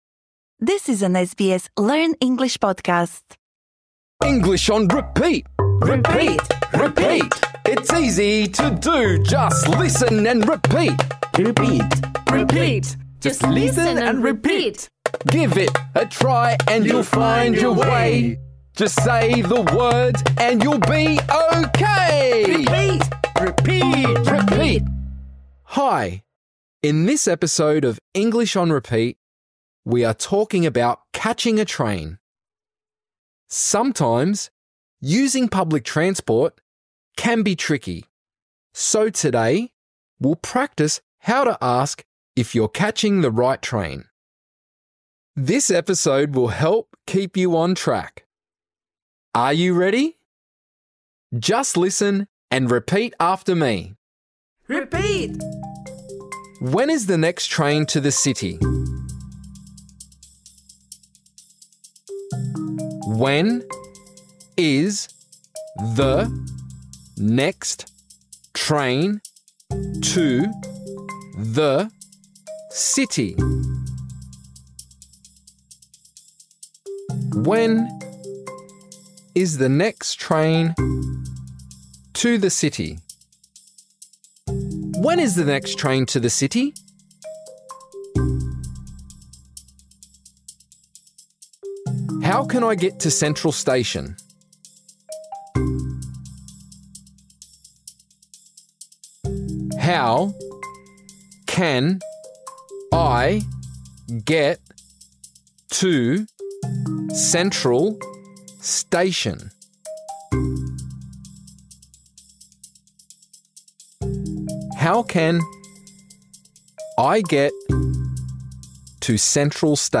| I’ll meet you on Platform 1 This lesson is designed for easy-level learners.